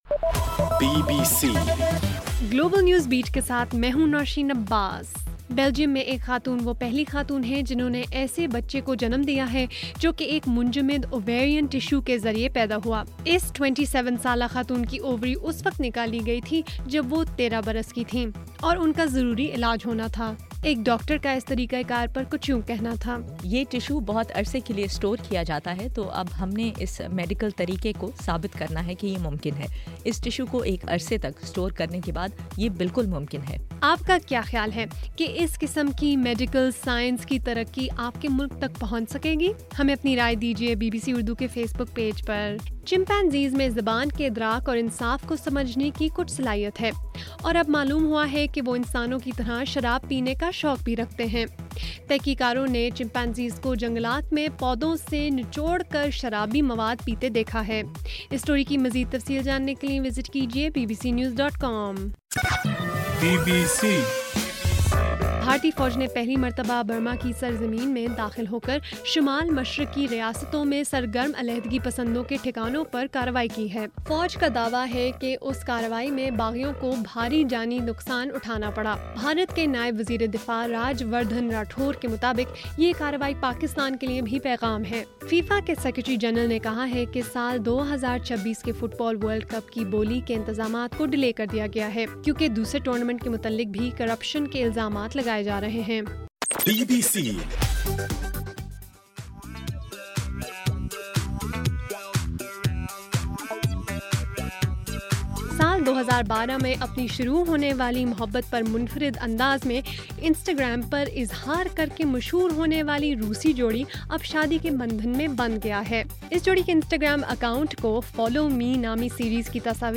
جون 10: رات 8 بجے کا گلوبل نیوز بیٹ بُلیٹن